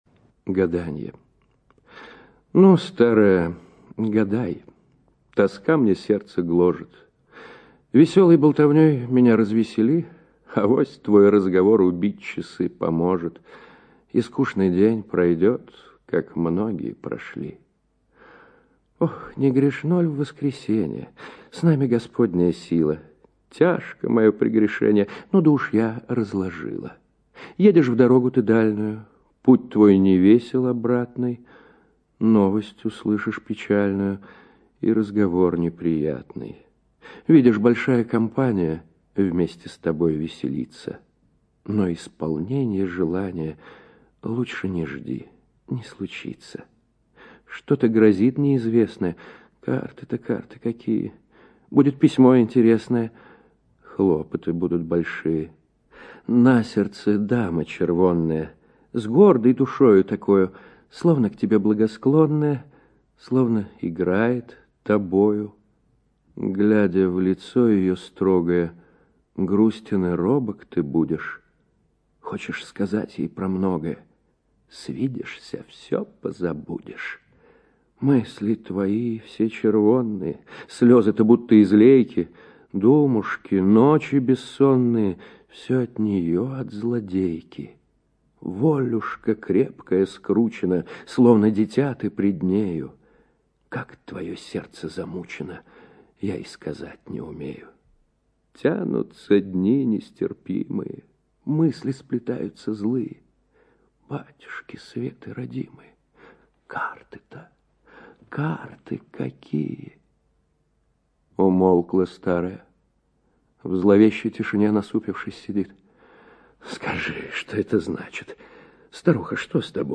ЖанрПоэзия